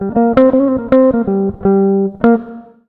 160JAZZ  8.wav